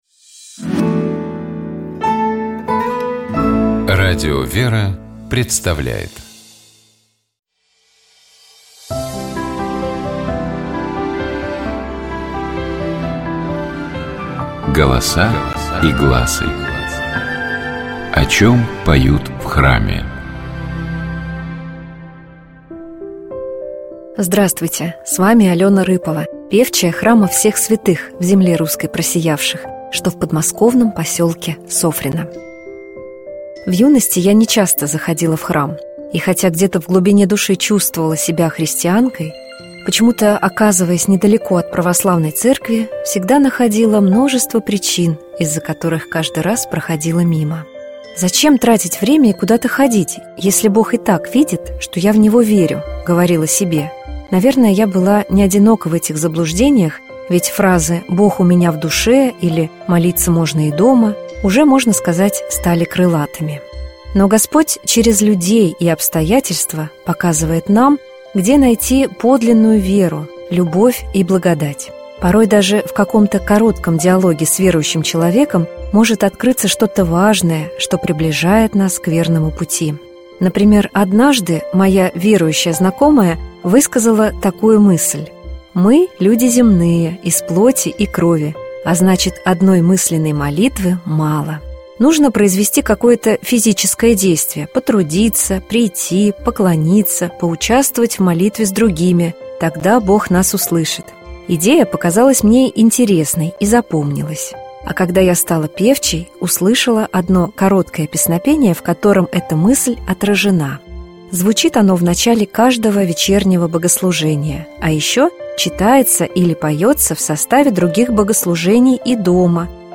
Golosa-i-glasy-Priidite-poklonimsja-nachalo-Vsenoshhnoj.mp3